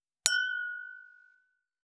303シャンパングラス,ワイングラス乾杯,イタリアン,バル,フレンチ,夜景の見えるレストラン,チーン,カラン,キン,コーン,チリリン,カチン,チャリーン,クラン,カチャン,クリン,シャリン,チキン,コチン,カチコチ,チリチリ,シャキン,カランコロン,パリーン,ポリン,トリン,
コップ効果音厨房/台所/レストラン/kitchen食器